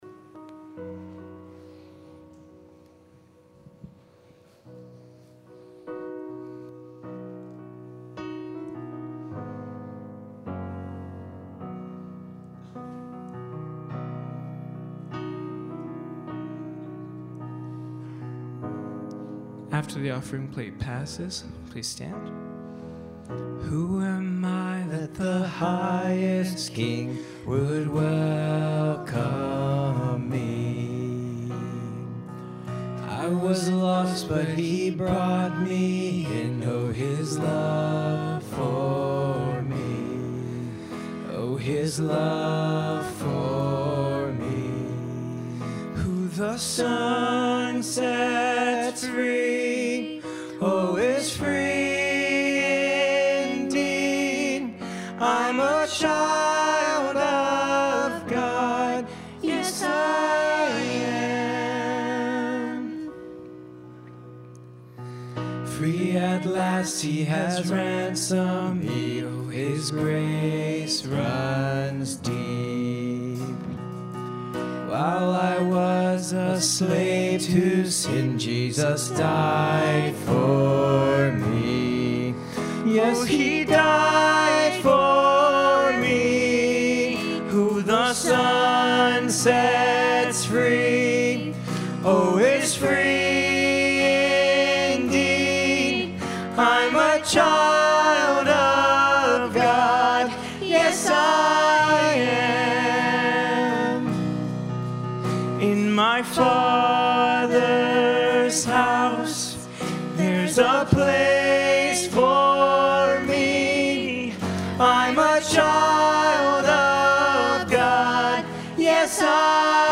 “Drink from Me” John 7:37-38 « FABIC Sermons